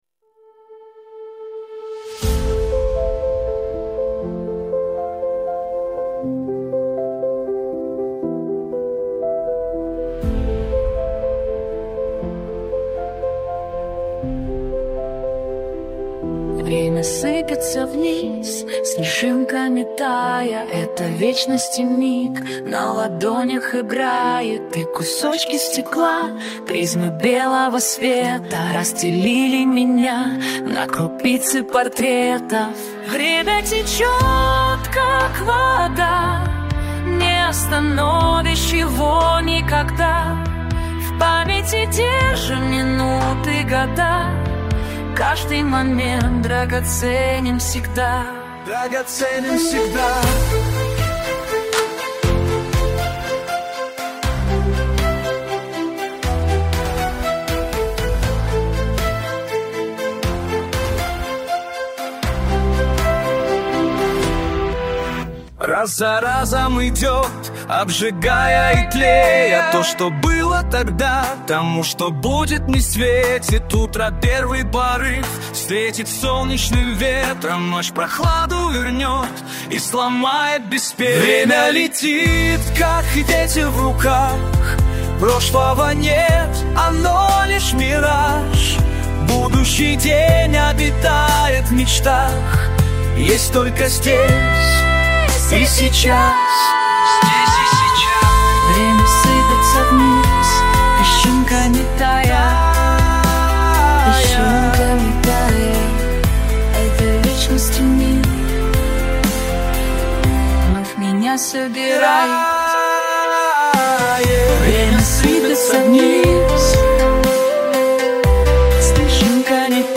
Аудиокниги - Примеры